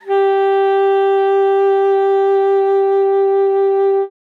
42e-sax07-g4.wav